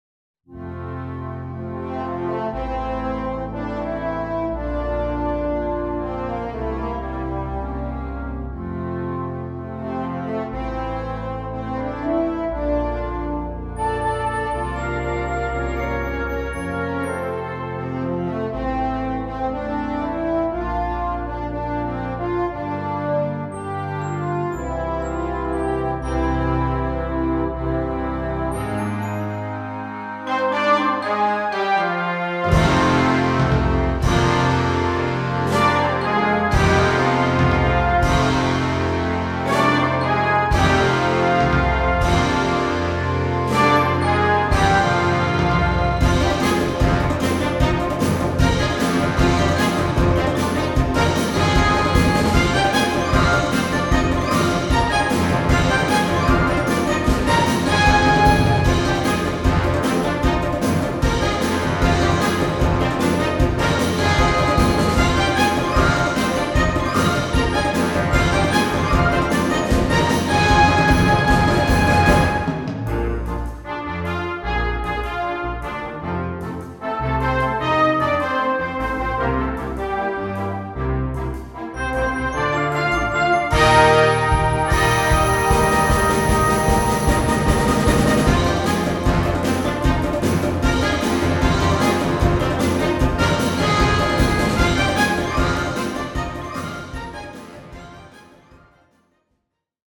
Gattung: Filmmusik für Blasorchester
Besetzung: Blasorchester